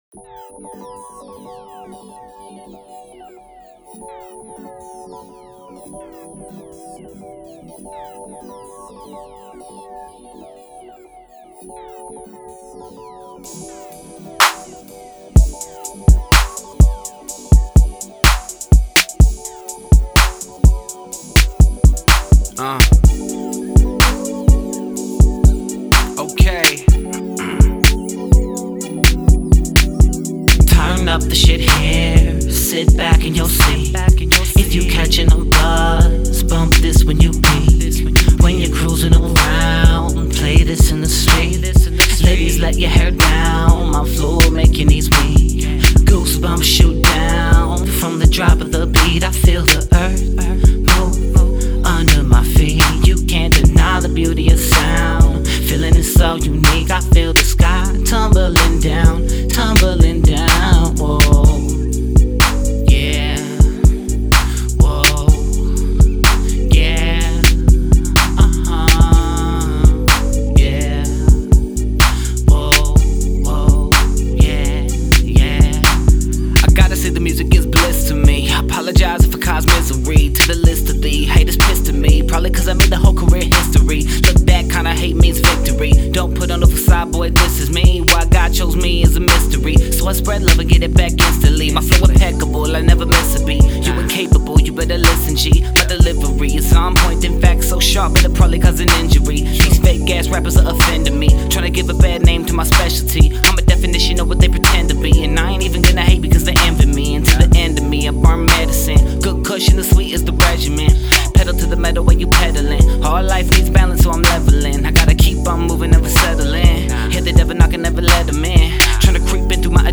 No doubt a landmark album for Seattle hip hop
Recorded at AD1 Studios, Seattle Washington